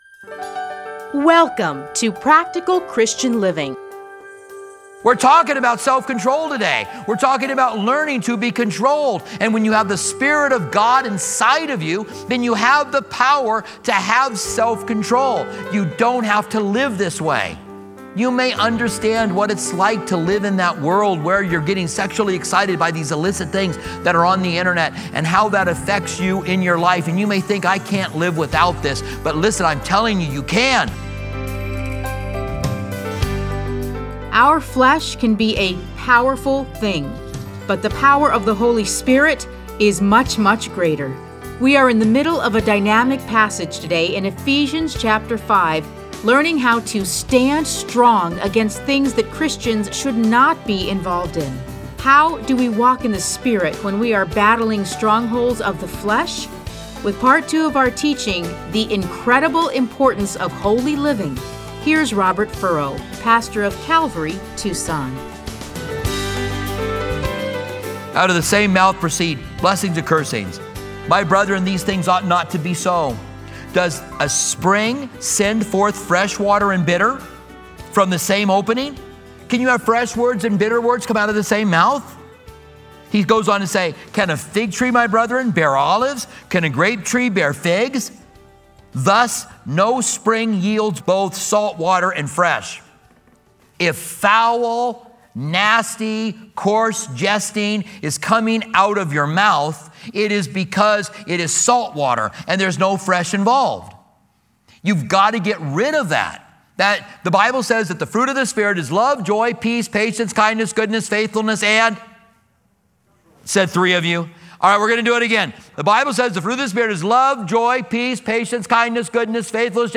Listen to a teaching from Ephesians 5:1-21.